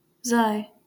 Xi (/z/